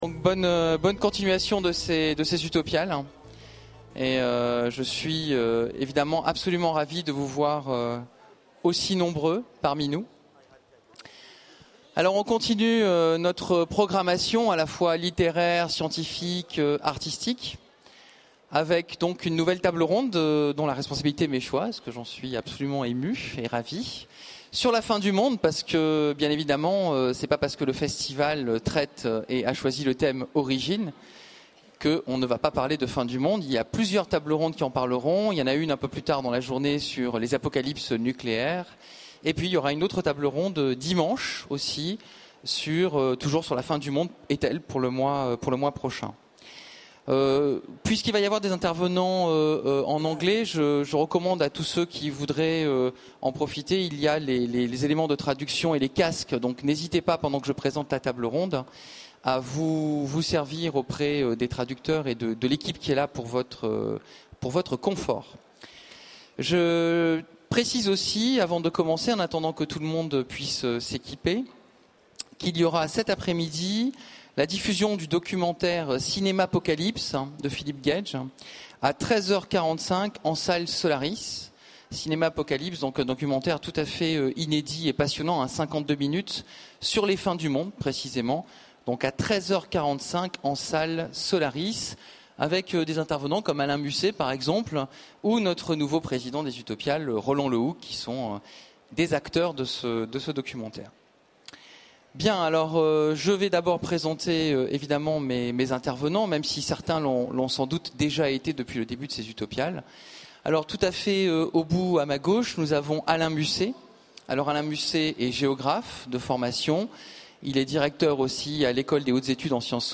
Utopiales 12 : Conférence La fin du monde est-elle une idée scientifique ?